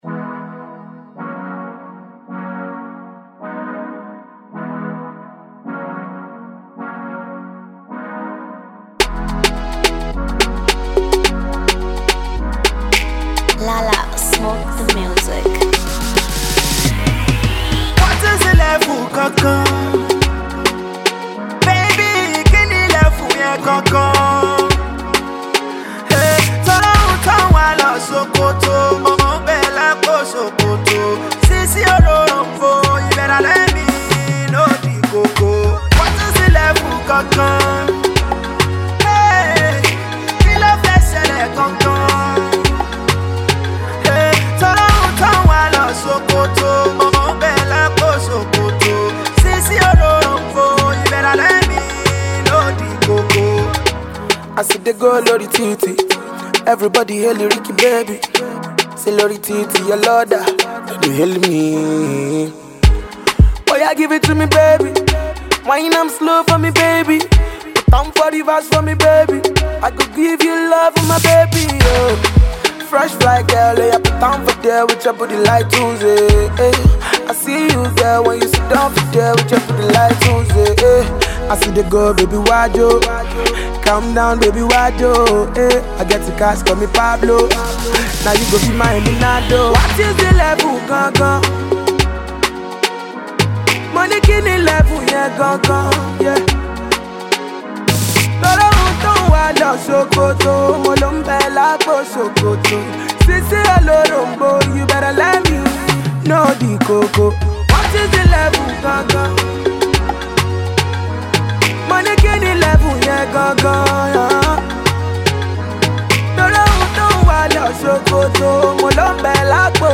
mid tempo vibe